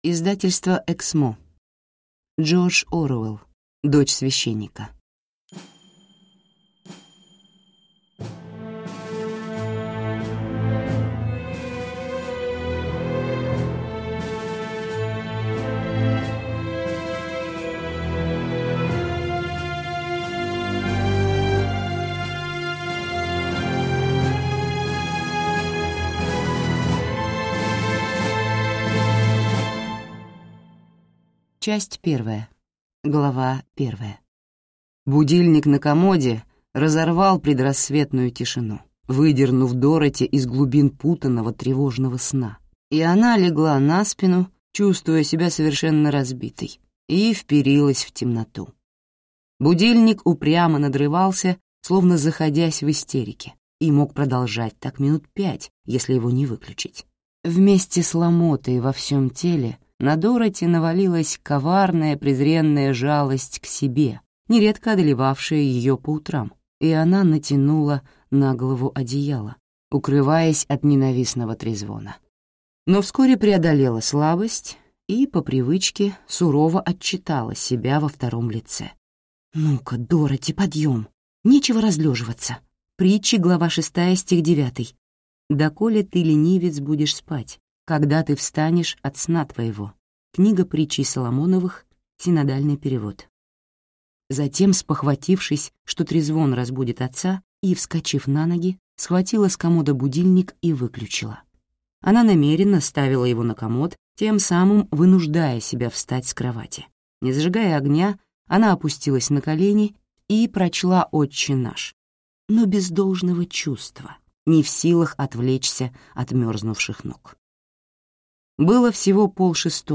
Аудиокнига Дочь священника | Библиотека аудиокниг